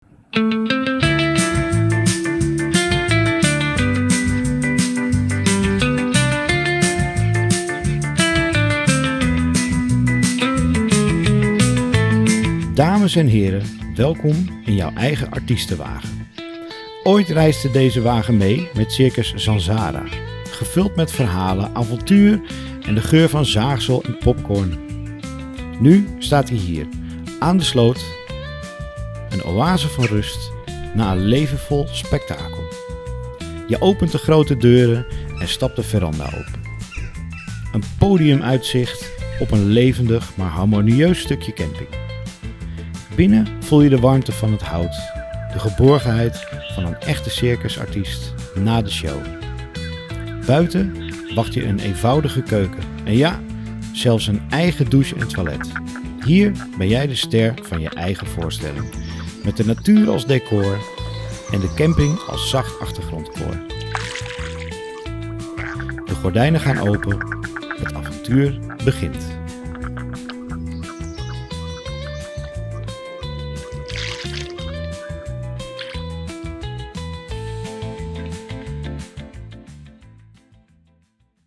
circuswagen-compleet-met-muziek.mp3